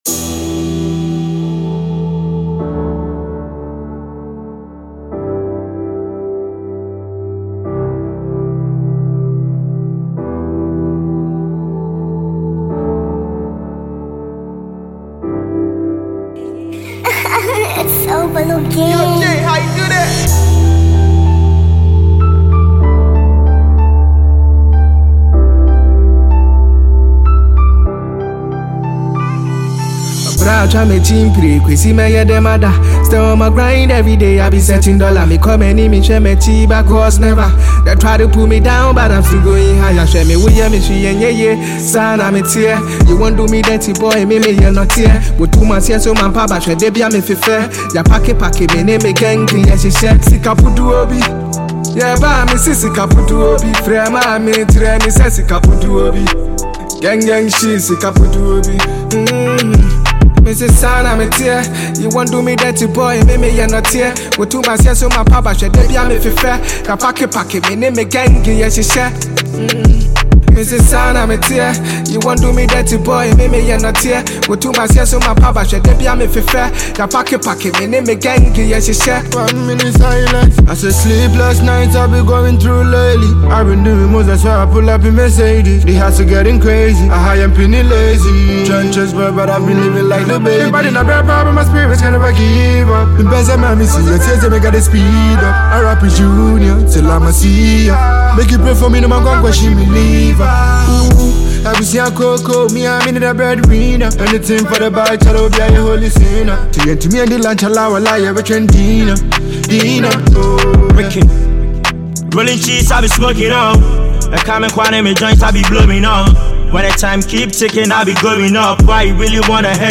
Ghanaian music group